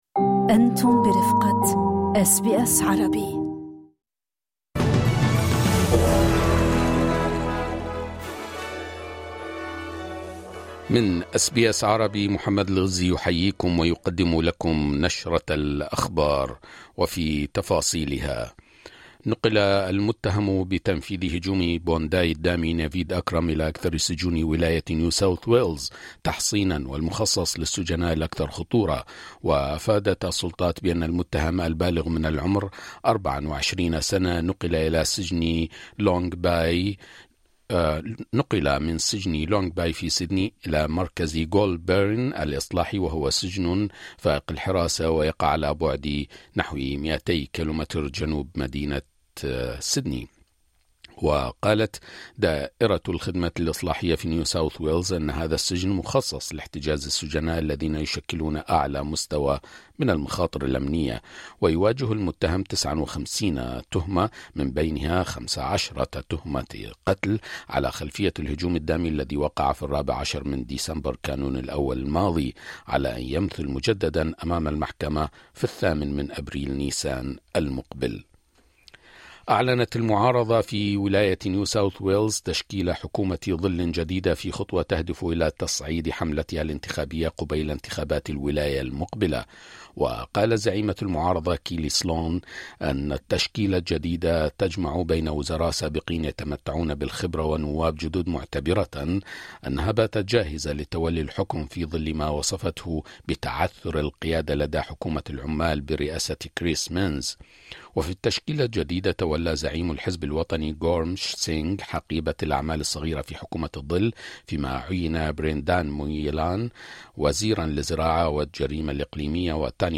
نشرة أخبار الظهيرة 06/01/2025
يمكنكم الاستماع الى النشرة الاخبارية كاملة بالضغط على التسجيل الصوتي أعلاه.